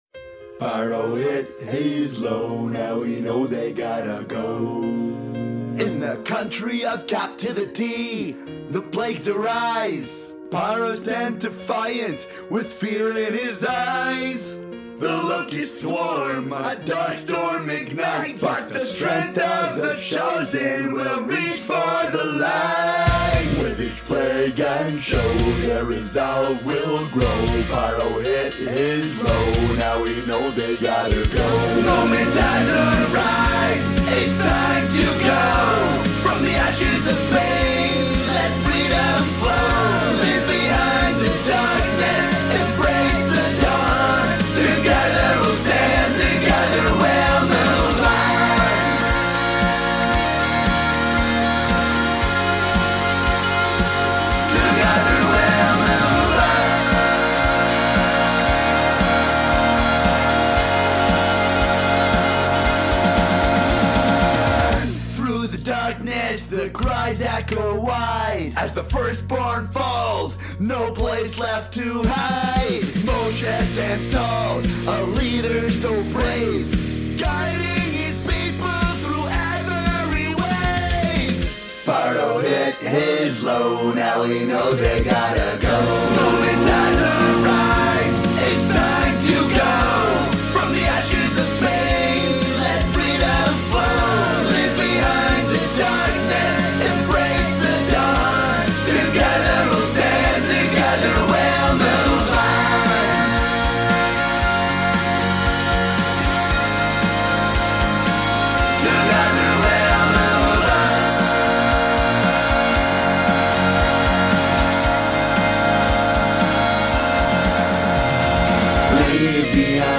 This week's Rockindacious Torah Tune!